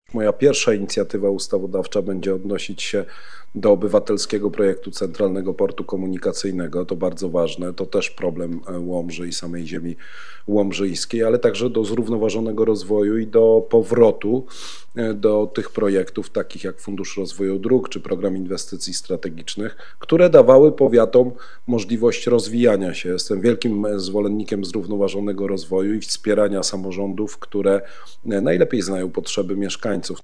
Był również gościem na antenie Radia Nadzieja.